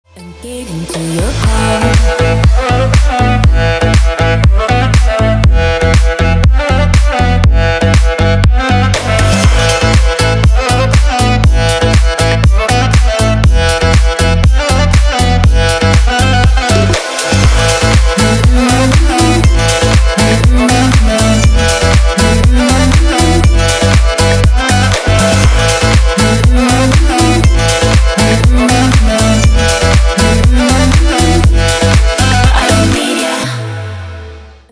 deep house
восточные мотивы
dance
Dance Pop